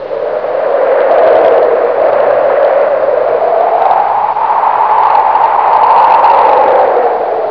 wind-sand.wav